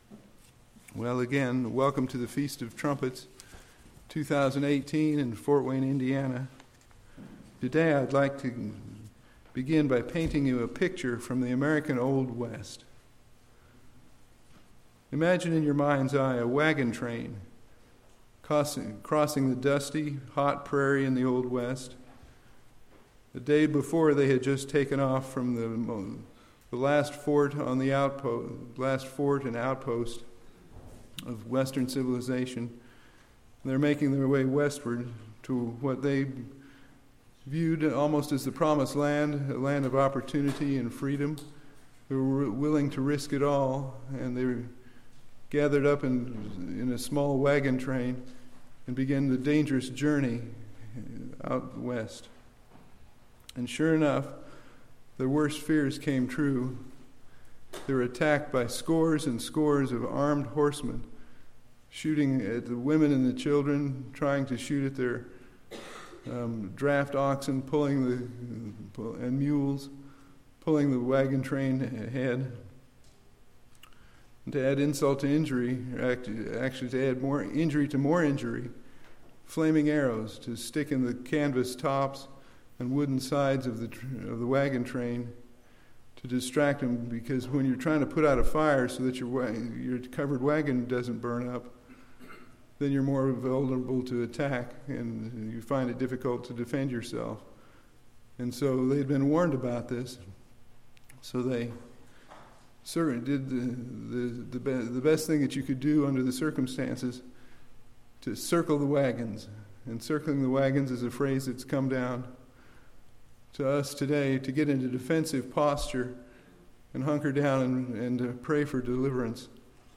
This sermon reflects on what the Feast of Trumpets means to us.